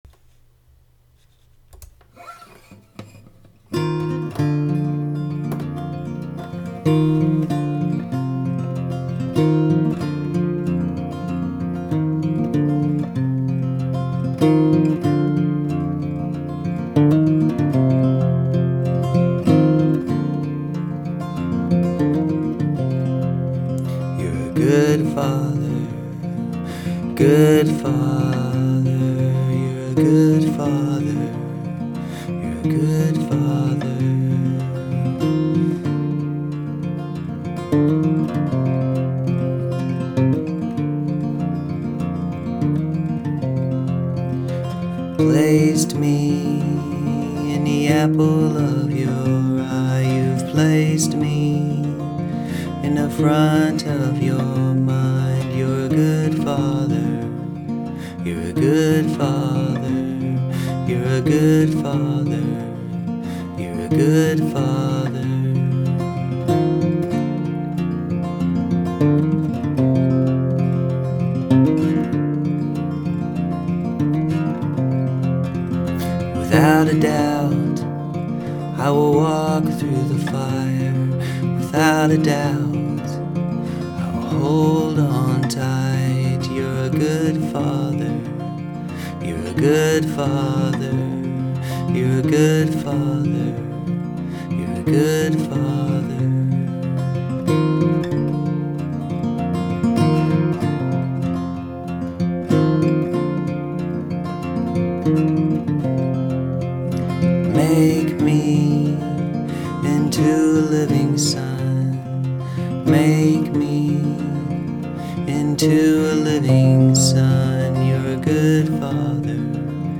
I tend towards the traditions where music was a social or intimate art form practiced by amateurs who spent more time in the dirt than the conservatory.   That maybe a justification for a poorer quality than what is available, so be it,  but it seems important to share a little more than just fragments.  The song is called Good Father.